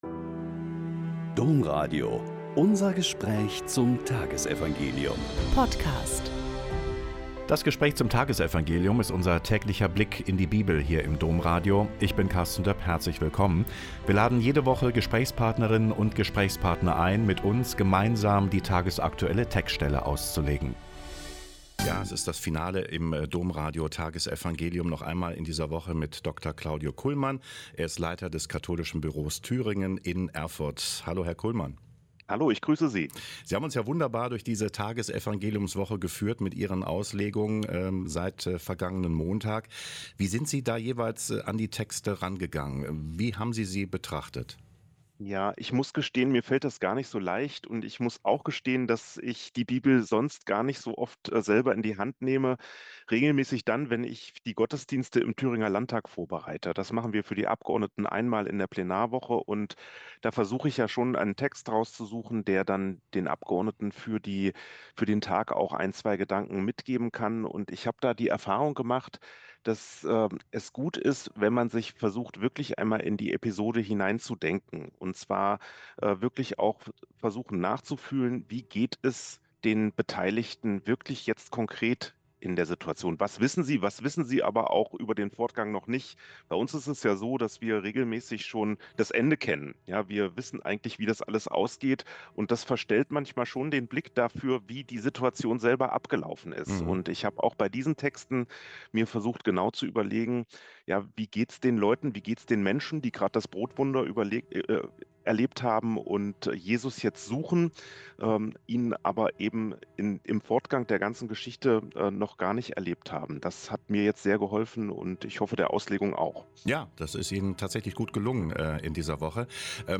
Joh 6,60-69 - Gespräch